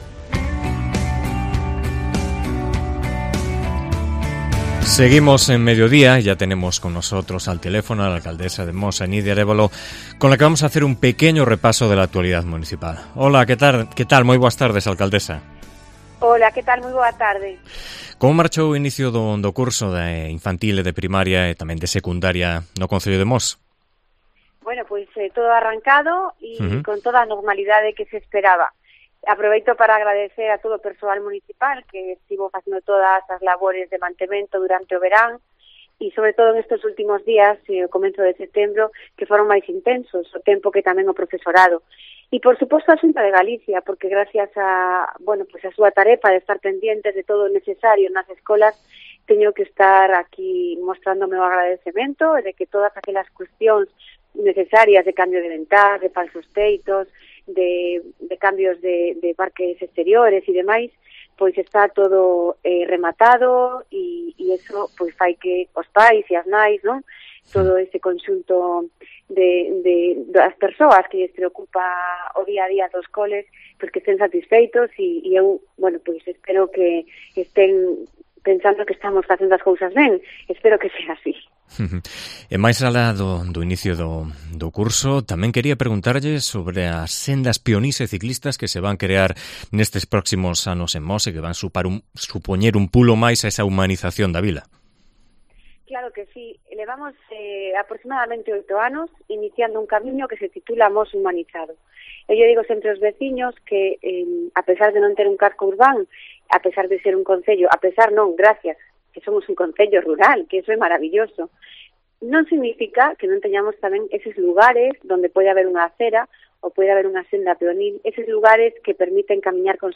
Entrevista con Nidia Arévalo, alcaldesa de Mos